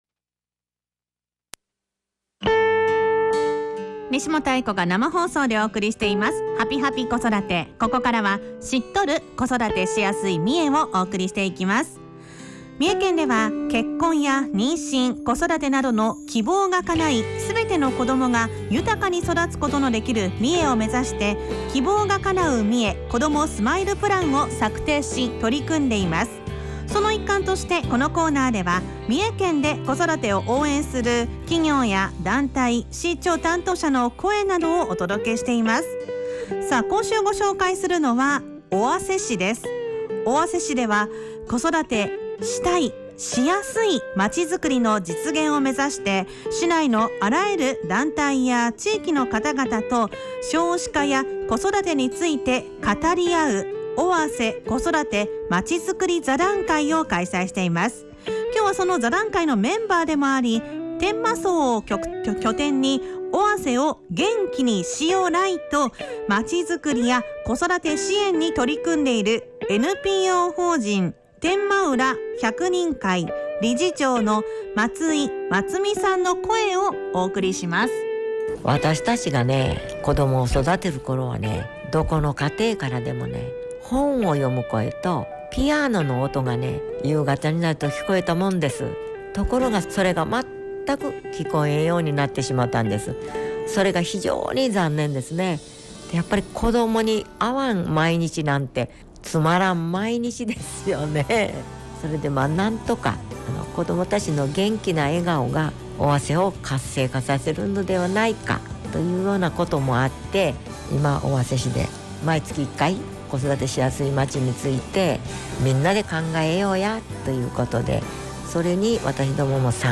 インタビュー
県内各市町の子育てを応援している団体などを取材し、地域で活躍されている方の生の声をFM三重「はぴぱぴ子育て」「EVENING COASTER」内で放送しました。